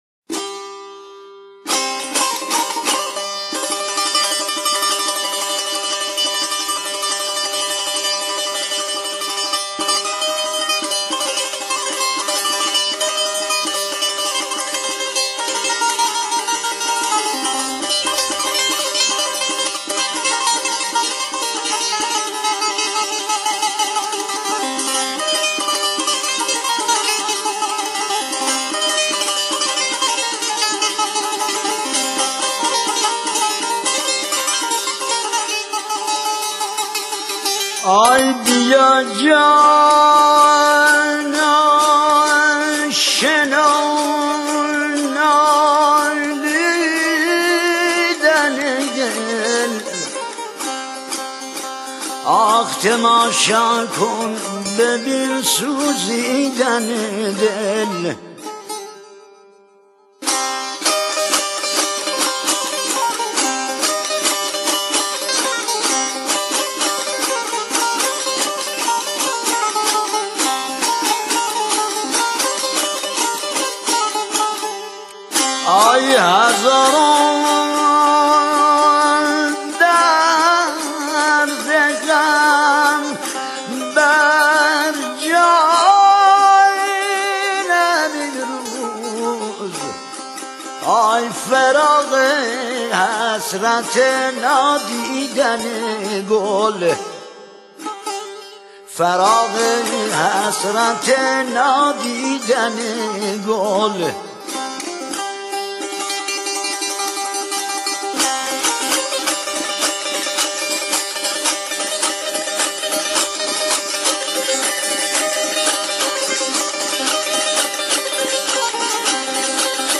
Music of Razavi Khorasan
hazaregi_trimmed.mp3